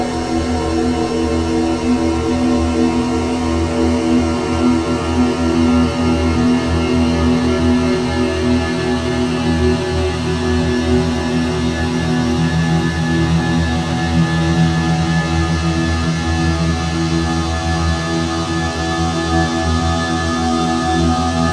Ambient drone